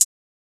Shortened Sizzle Hat (HAT).wav